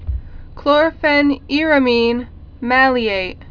(klôrfĕn-îrə-mēn)